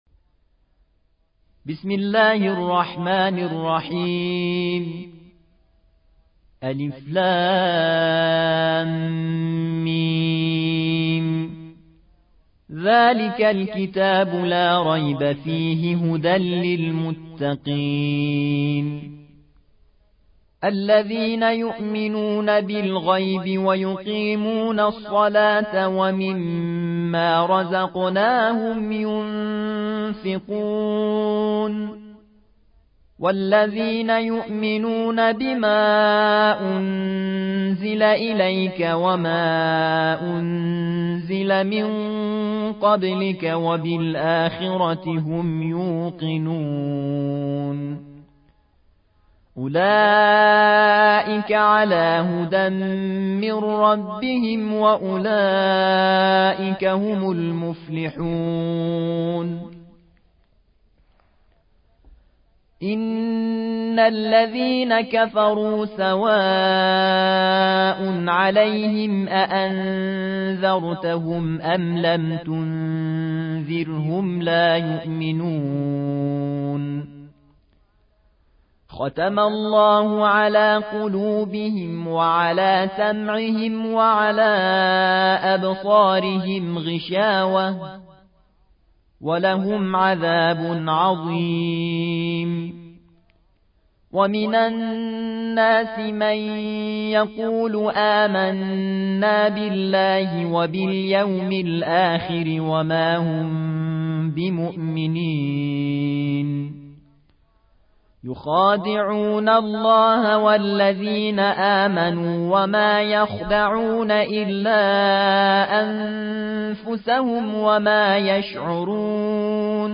2. سورة البقرة / القارئ